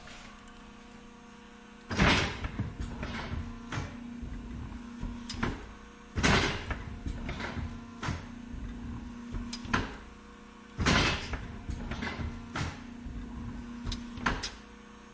金属加工厂 "机械液压金属切割器室
描述：机液压金属切割机roomy.flac
Tag: 切割机 液压 金属